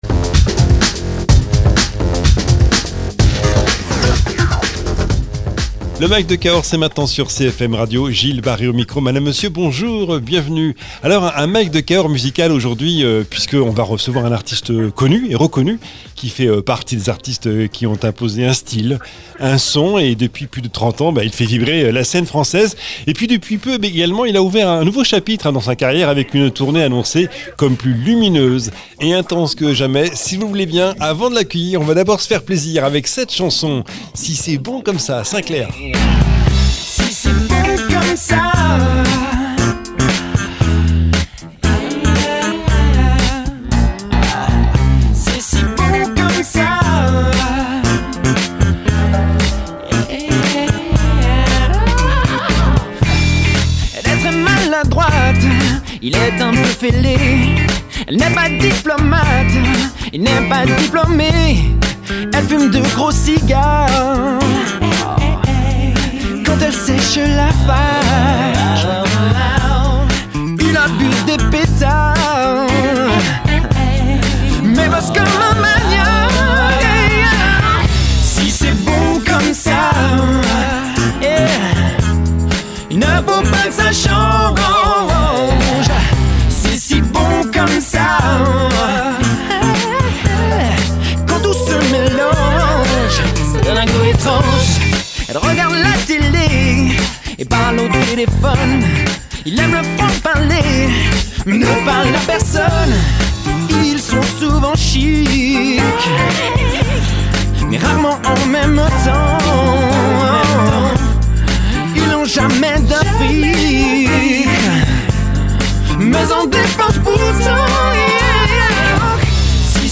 Invité(s) : Sinclair, artiste